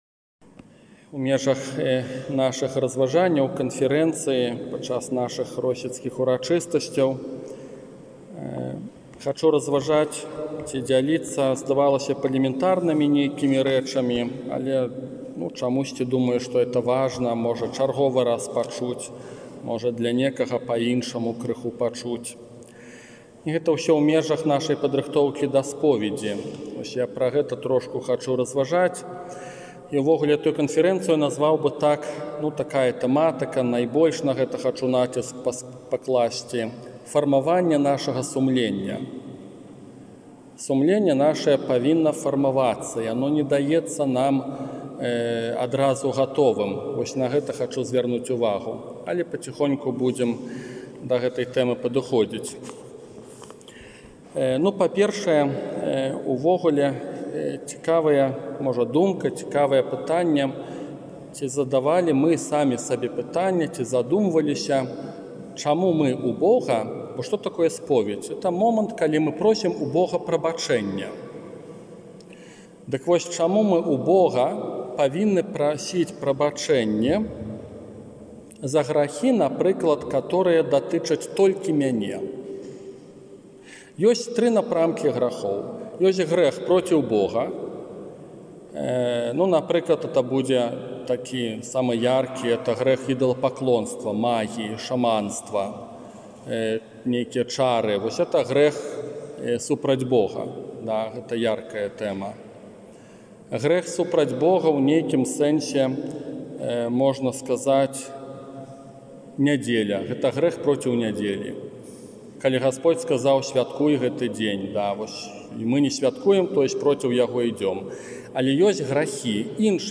Дадзеная катэхэза прагучала падчас урачыстасцяў у Росіцы 6 жніўня 2022 года